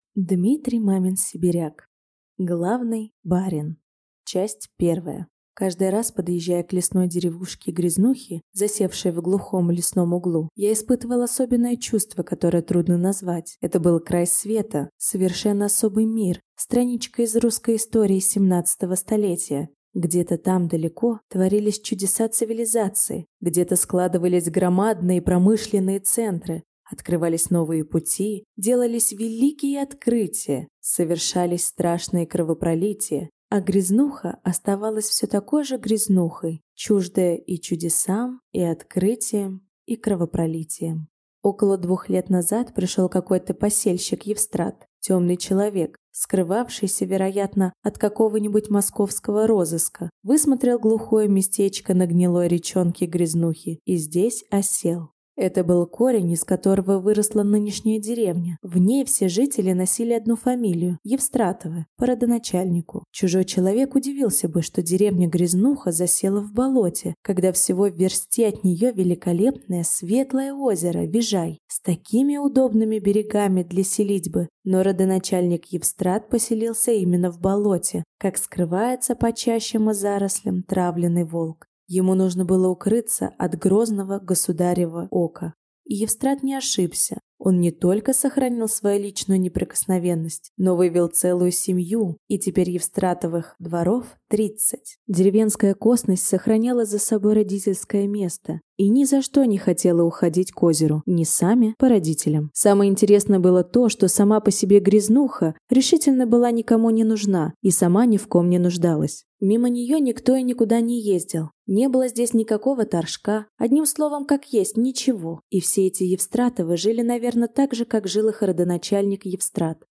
Аудиокнига Главный барин | Библиотека аудиокниг